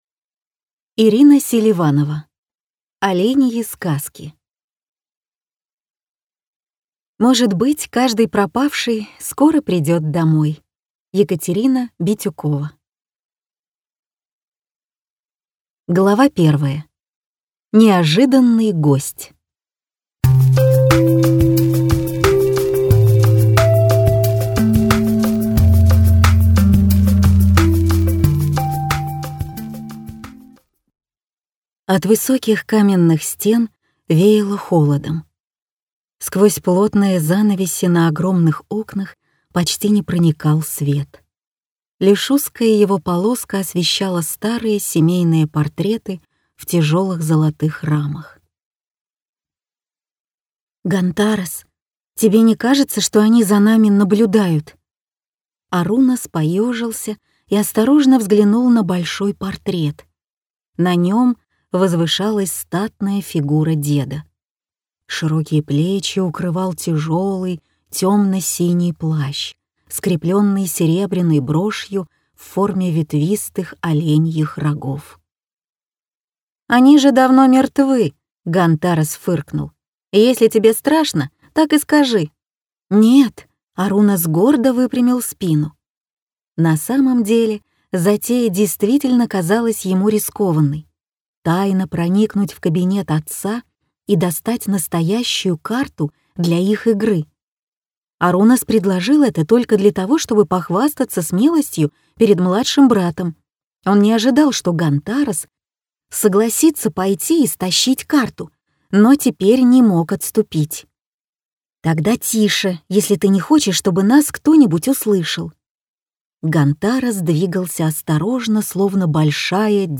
Аудиокнига Оленьи сказки | Библиотека аудиокниг